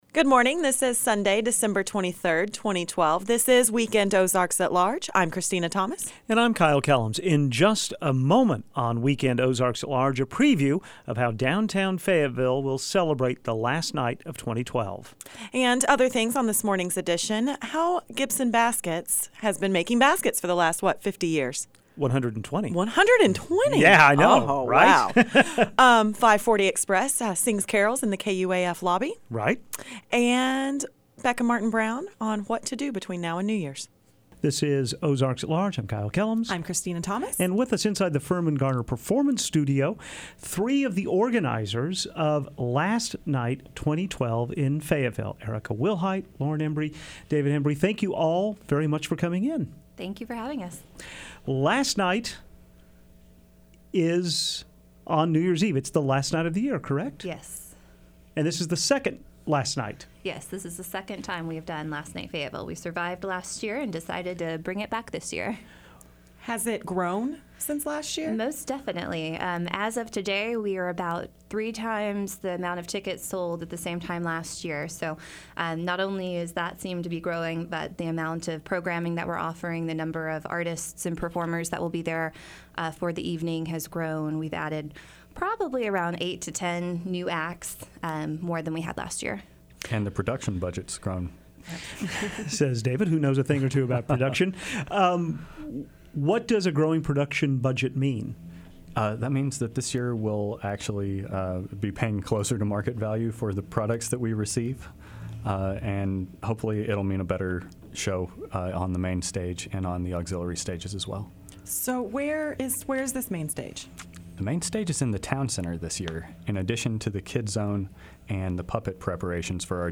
Plus, how Gibson Baskets has been making making its signature product for 120 years, and Five Forty Express sings carols in the KUAF lobby.